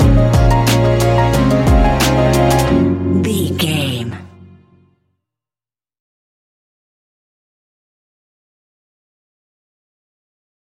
Ionian/Major
F♯
chilled
Lounge
sparse
new age
chilled electronica
ambient
atmospheric
instrumentals